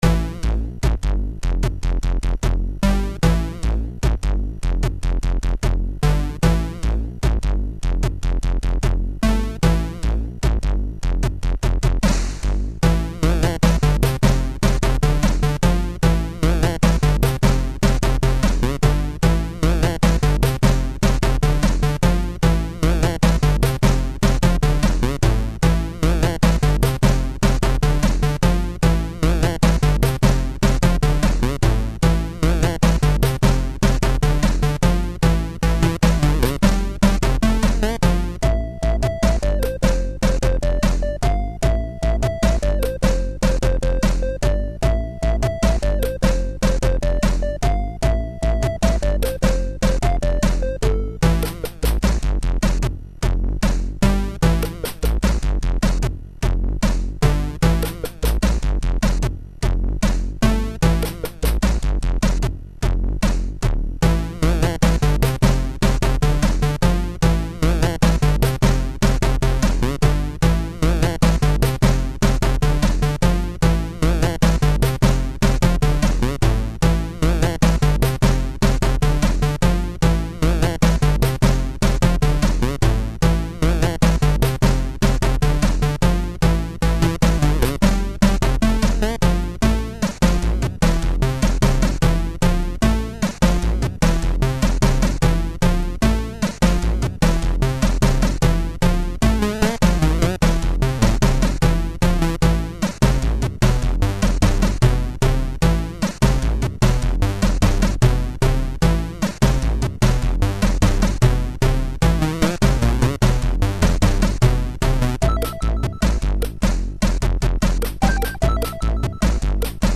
Music, as played by SIDMan